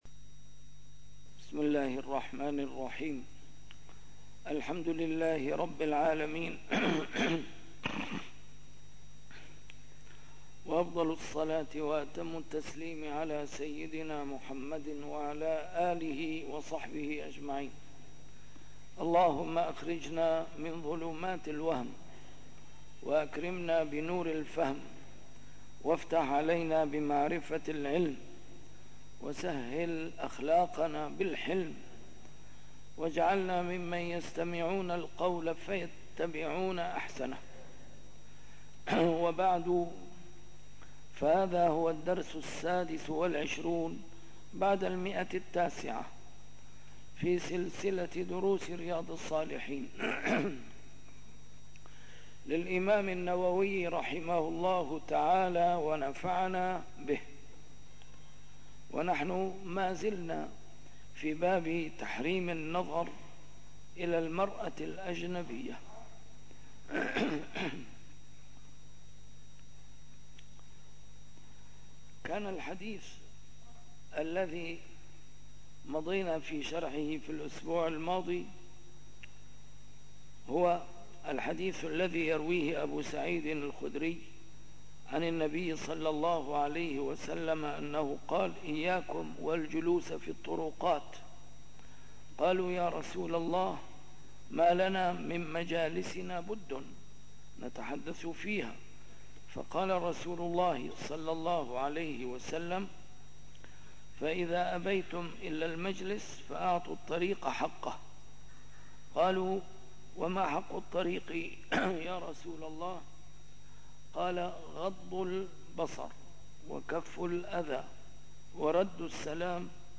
A MARTYR SCHOLAR: IMAM MUHAMMAD SAEED RAMADAN AL-BOUTI - الدروس العلمية - شرح كتاب رياض الصالحين - 926- شرح رياض الصالحين: تحريم النظر إلى المرأة الأجنبية